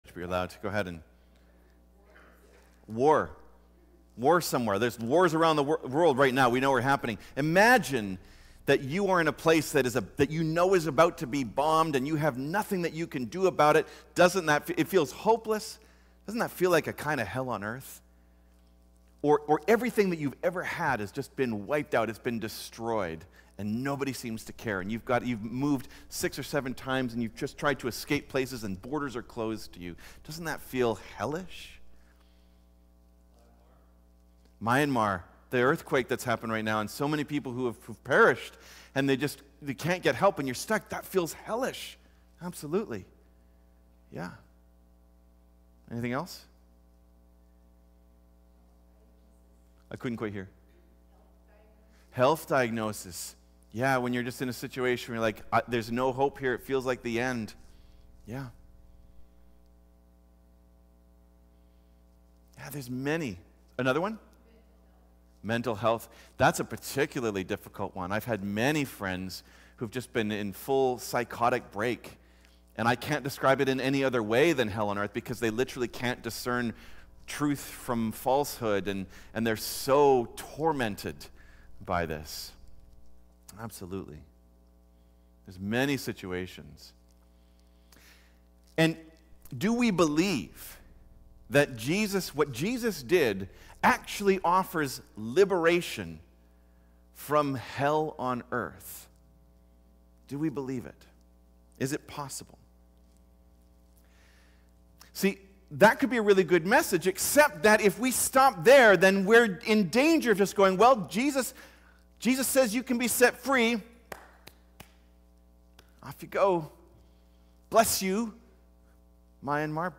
Cascades Church Sermons Harrowing Hell Play Episode Pause Episode Mute/Unmute Episode Rewind 10 Seconds 1x Fast Forward 30 seconds 00:00 / 21:20 Subscribe Share Apple Podcasts RSS Feed Share Link Embed